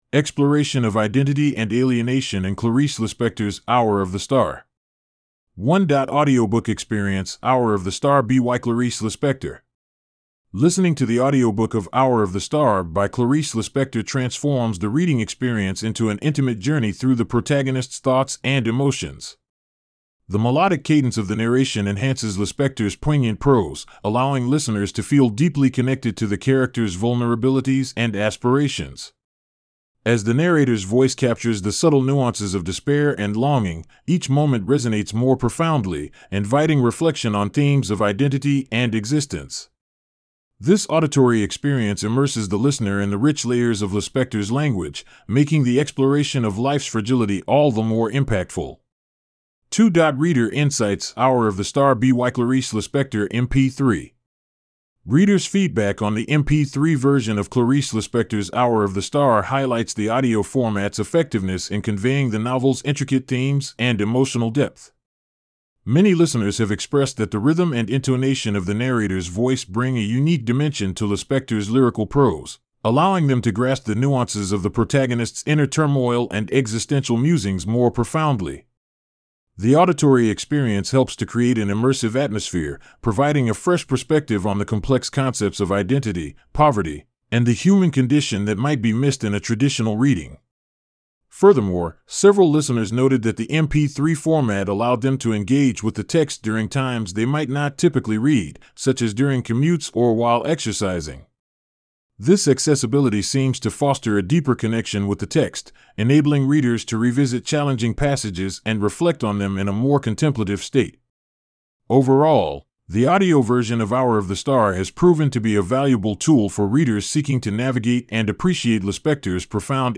1.Audiobook Experience:Hour Of The Star BYClarice Lispector Listening to the audiobook of "Hour of the Star" by Clarice Lispector transforms the reading experience into an intimate journey through the protagonist's thoughts and emotions.